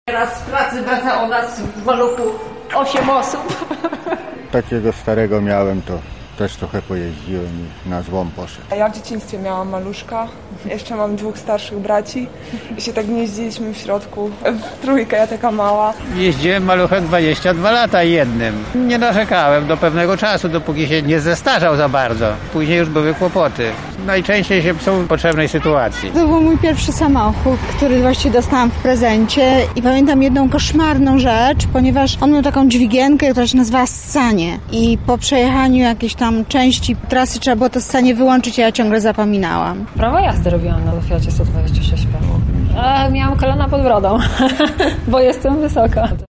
Zapytaliśmy lublinian jakie wspomnienia mają z maluchem: